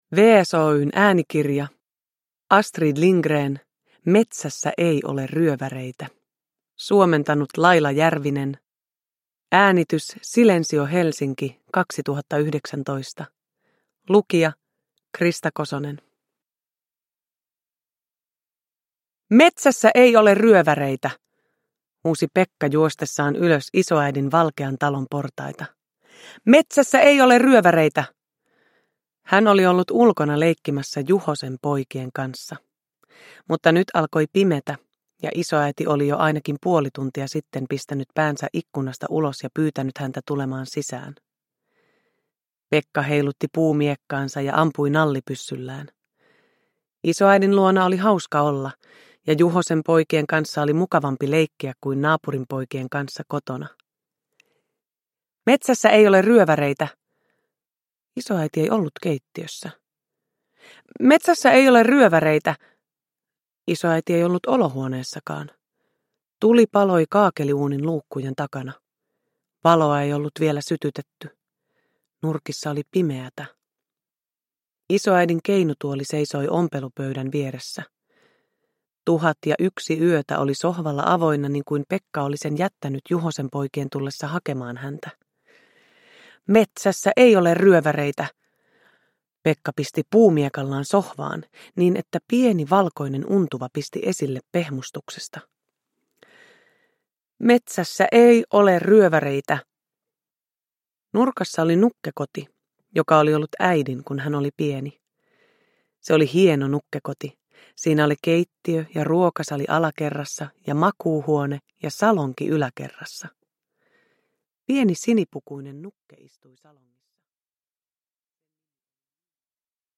Metsässä ei ole ryöväreitä – Ljudbok – Laddas ner
Uppläsare: Krista Kosonen